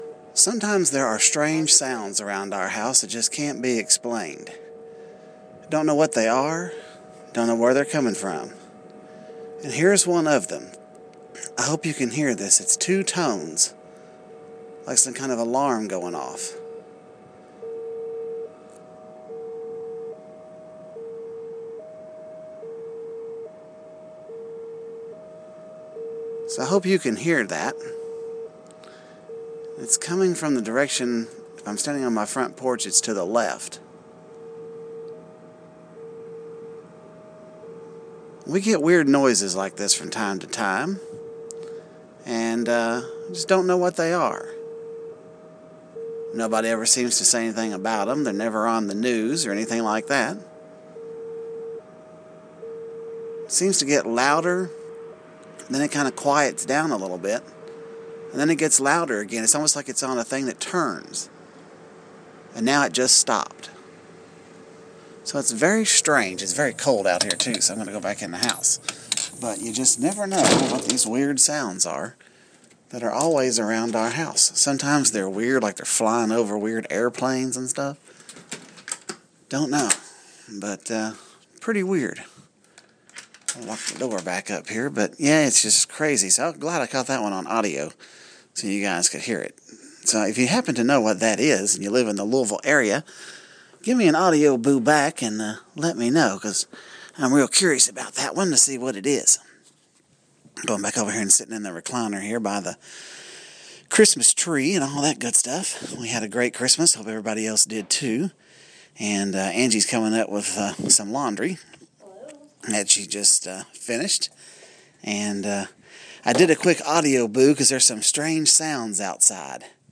Strange sounds from Old Naval ordnance